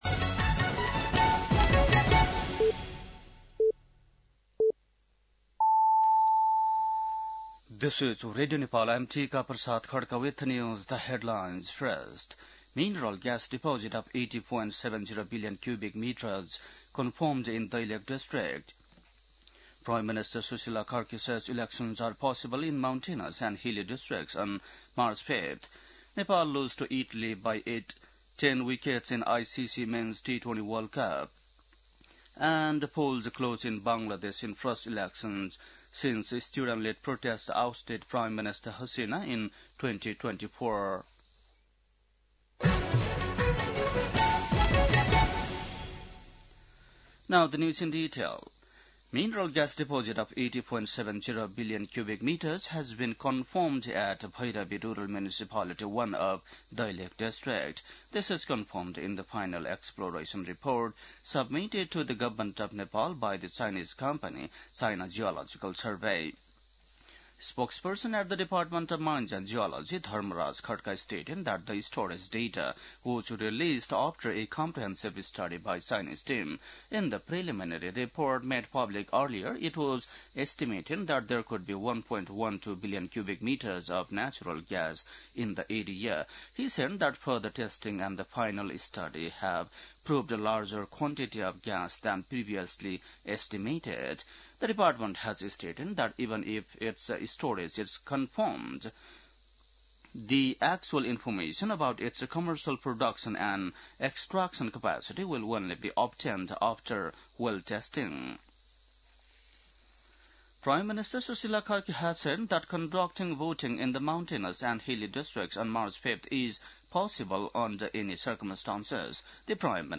बेलुकी ८ बजेको अङ्ग्रेजी समाचार : २९ माघ , २०८२
8.-pm-english-news-1-3.mp3